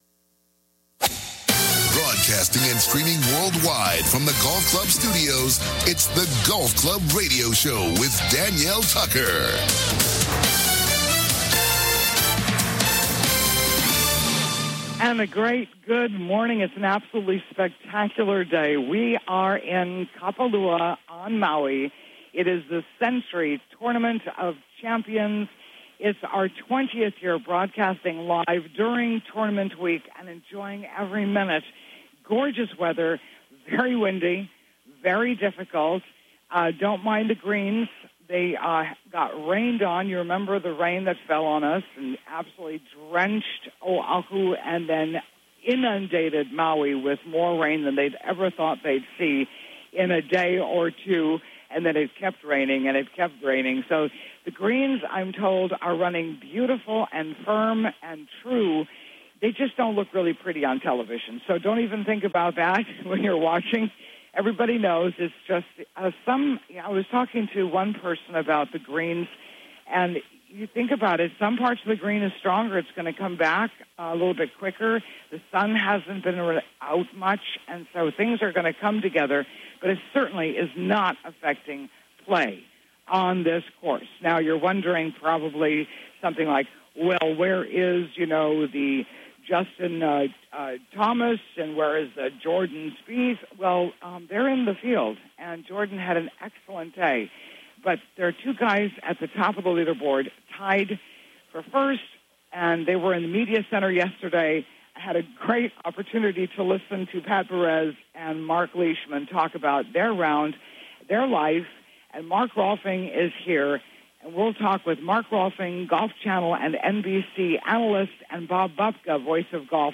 Kapalua Plantion Course In the Clubhouse: Mark Rolfing Golf Channel and NBC Golf Analyst